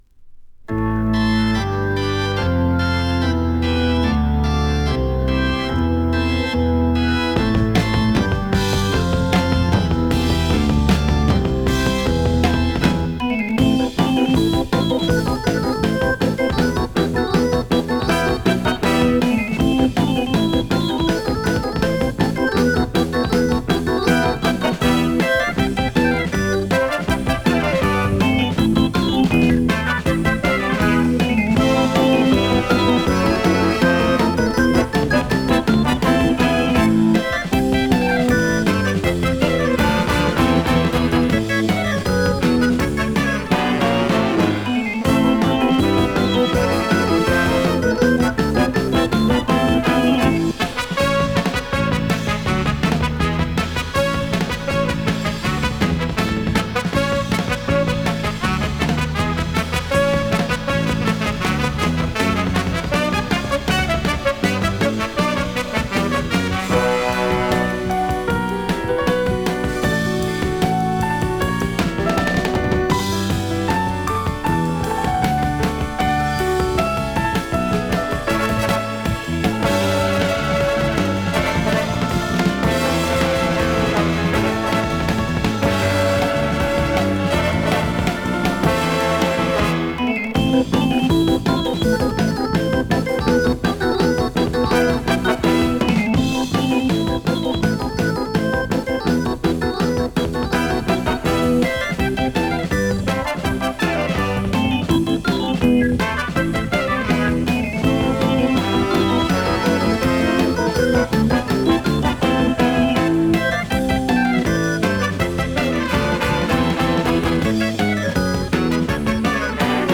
Трек непосредственно с винила.